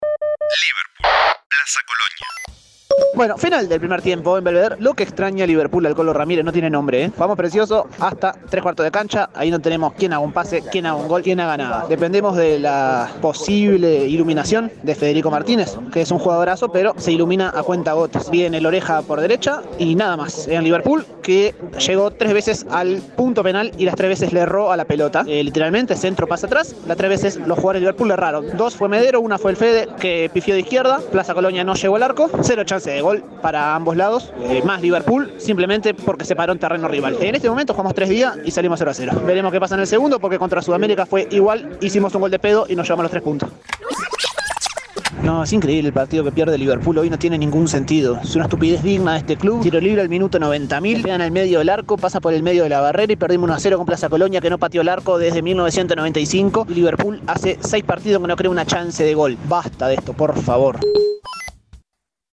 Los partidos de la sexta fecha del Torneo Clausura del fútbol uruguayo vistos y comentados desde las tribunas.